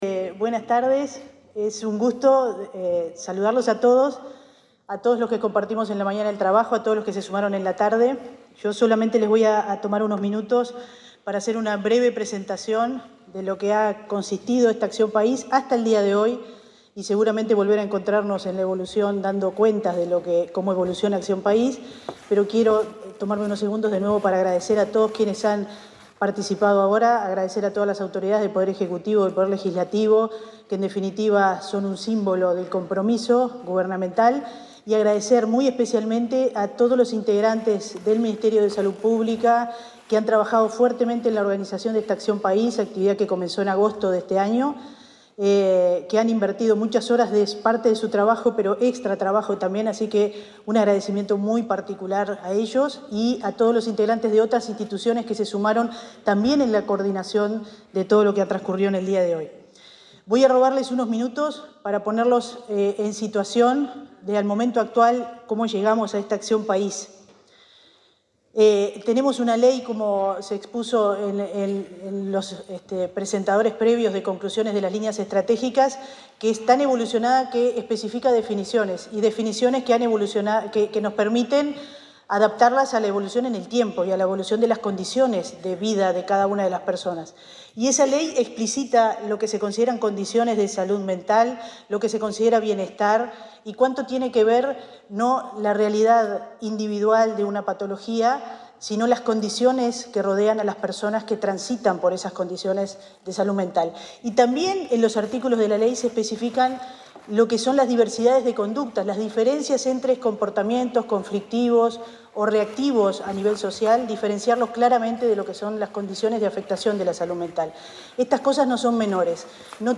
Palabras de autoridades del Ministerio de Salud Pública
La ministra de Salud Pública, Cristina Lustemberg, y la directora general de Salud, Fernanda Nozar, cerraron la jornada Acción País por la Salud